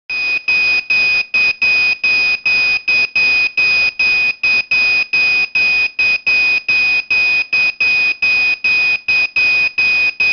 2. the buzzer must give a warning signal as you can hear here: (
Audition reception failure).
After you switch the transmitter ON again the warning signal disappears and the red LED is OFF.